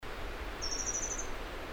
Erpornis à ventre blanc ( Erpornis zantholeuca ) ssp griseiloris
Cri enregistré le 09 mai 2012, en Chine, province du Fujian, réserve de Dai Yun Shan.